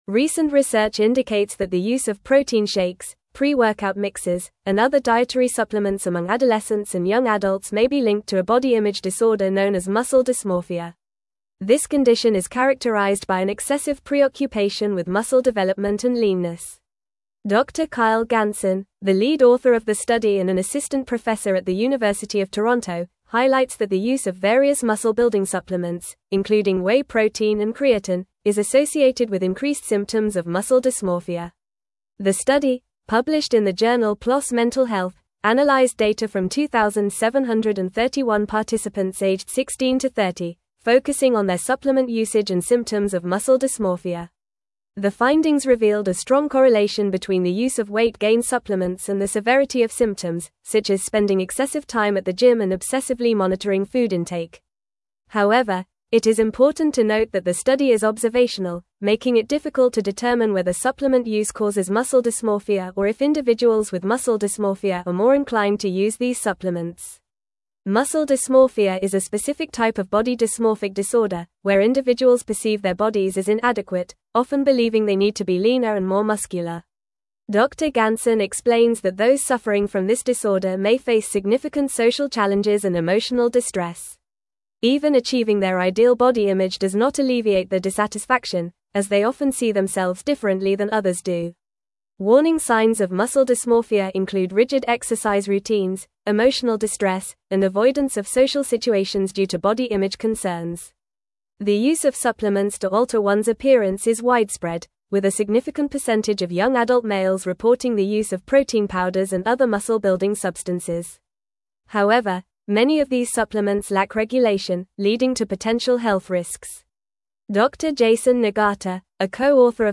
Fast
English-Newsroom-Advanced-FAST-Reading-Link-Between-Supplements-and-Muscle-Dysmorphia-in-Youth.mp3